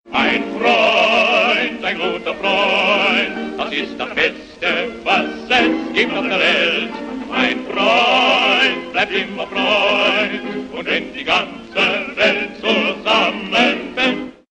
Garage Synth